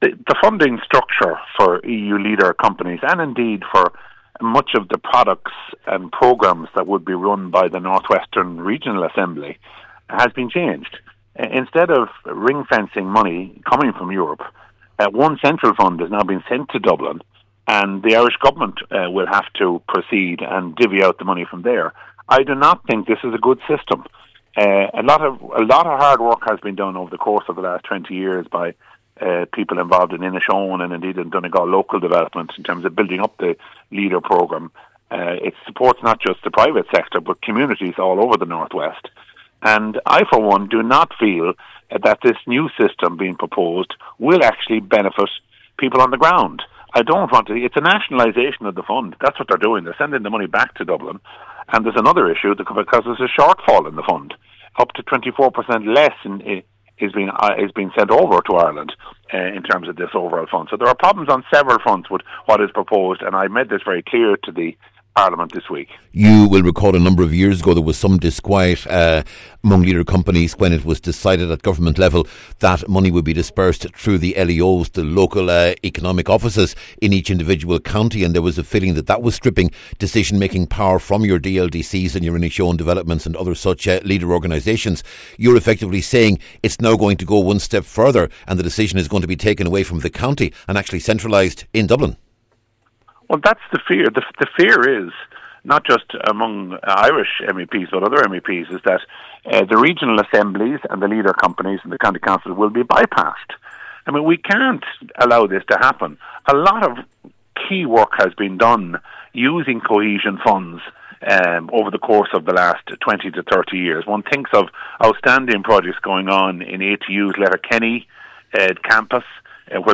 The warning was delivered this week at the European Parliament’s Regional Development Committee by Ciaran Mullooly.